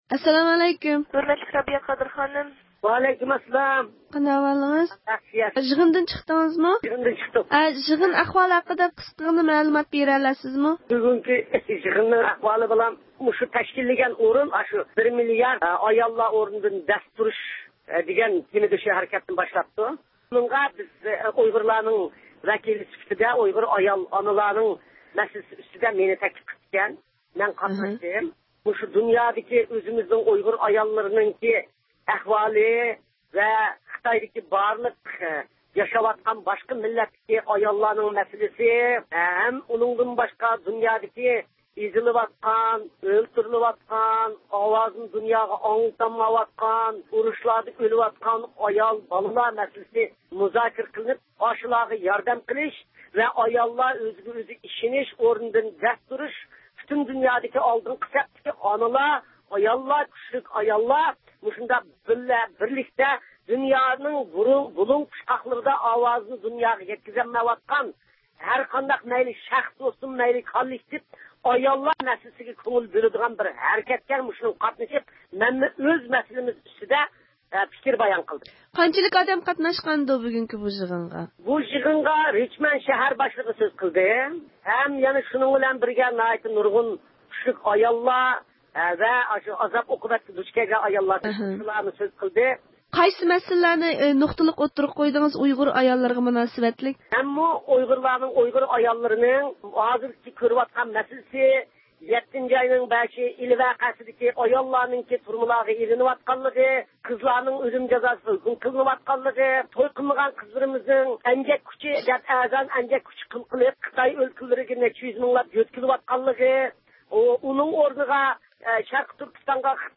مۇخبىرىمىزنىڭ زىيارىتىنى قوبۇل قىلغان ئۇيغۇر مىللىي ھەرىكىتى رەھبىرى رابىيە قادىر خانىم ئۇيغۇرلارنىڭ نۆۋەتتىكى سىياسىي، ئىقتىسادى، كىشىلىك ھوقۇق، مەدەنىي ۋە دىنىي ۋەزىيىتىنىڭ ئۇيغۇر ئاياللىرىنىڭ ئورنىدىن دەس تۇرۇشىنى تەخىرسىزلىك بىلەن كۈتۈۋاتقانلىقىنى ئوتتۇرىغا قويدى..